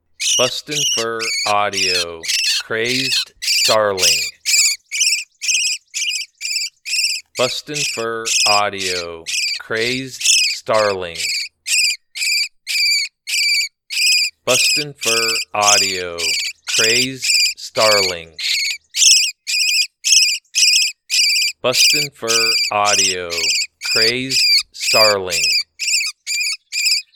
Juvenile Starling in distress, excellent for calling all predators
BFA Crazed Starling Sample.mp3